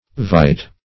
vitae - definition of vitae - synonyms, pronunciation, spelling from Free Dictionary